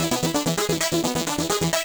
Index of /musicradar/8-bit-bonanza-samples/FM Arp Loops
CS_FMArp B_130-E.wav